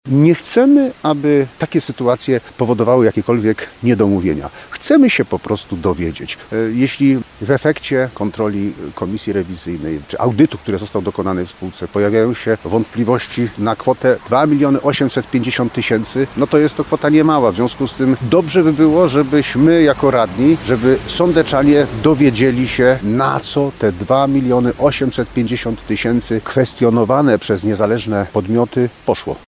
Radni pytali podczas konferencji prasowej między innymi o to, dlaczego wydano blisko 400 tysięcy złotych na umowy, między innymi z poprzednimi członkami zarządu Sądeckich Wodociągów.